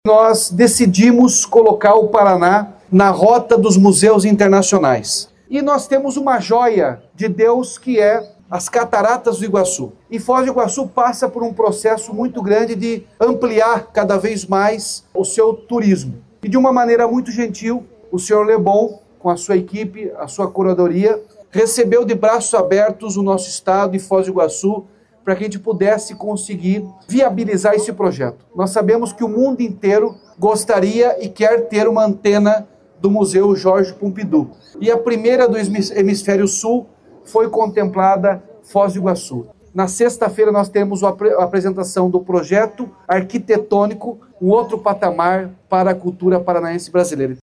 Sonora do governador Ratinho Junior sobre o início das ativações do Centre Pompidou Paraná